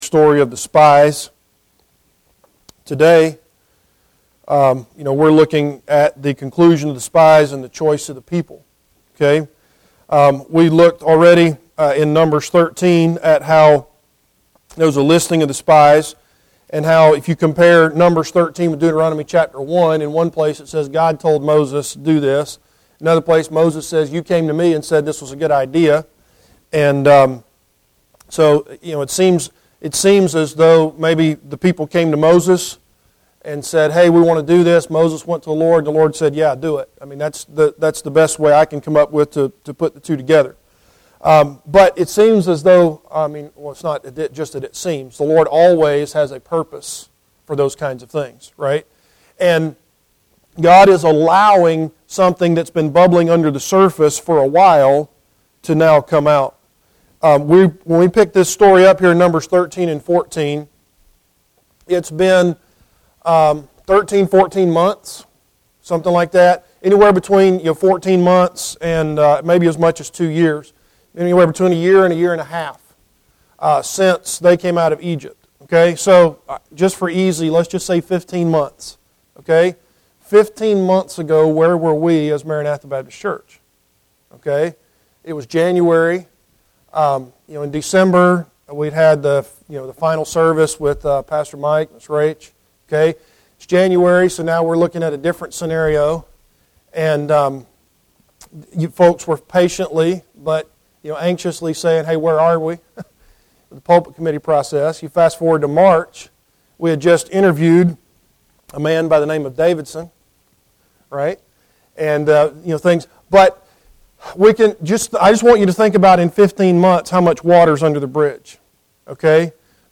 Numbers 13-14:6 Service Type: Adult Sunday School Class Bible Text